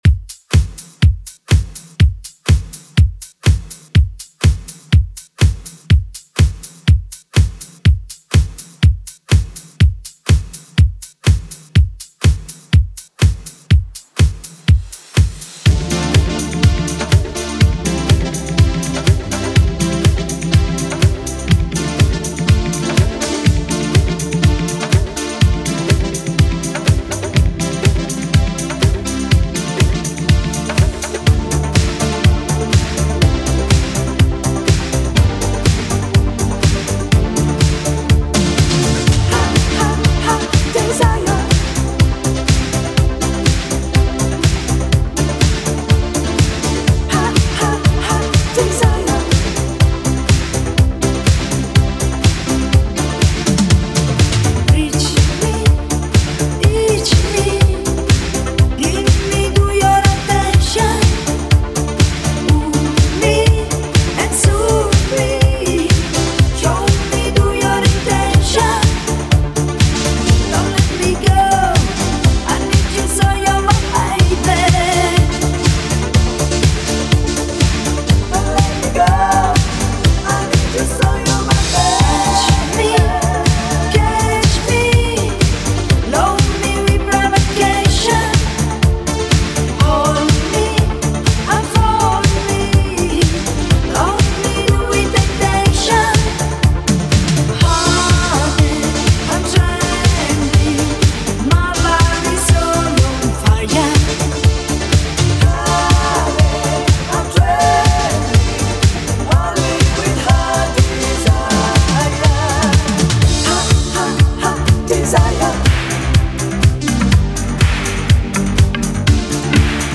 Genre: 80's Version: Clean BPM: 120